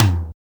626 TOM1 LO.wav